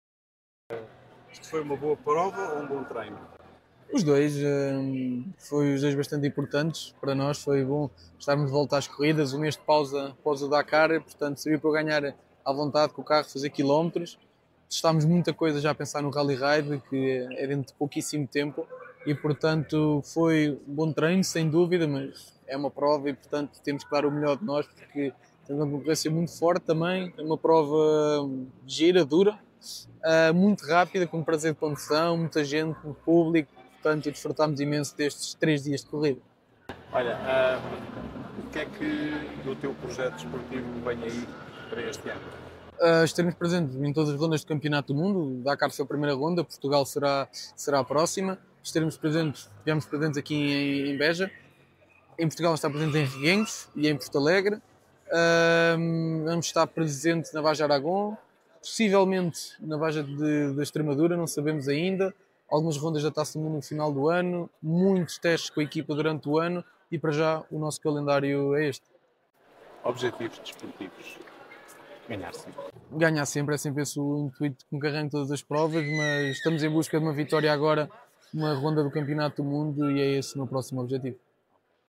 Entrevista Final